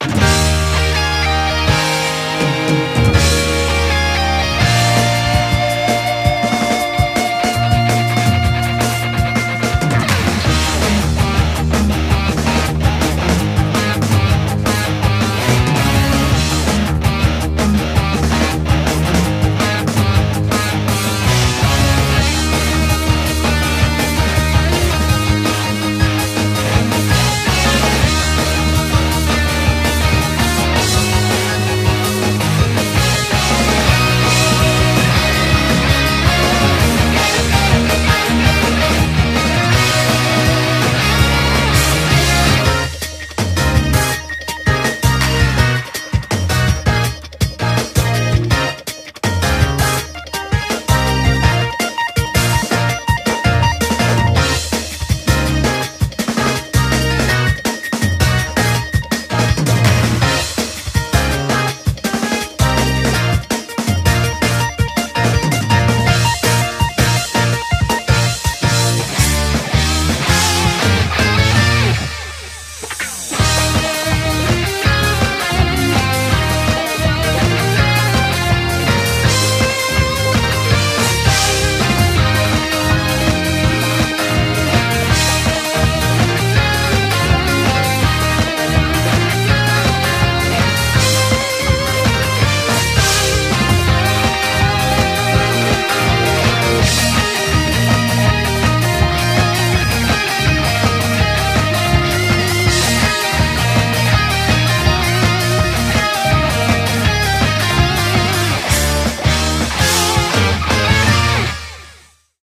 BPM164
MP3 QualityMusic Cut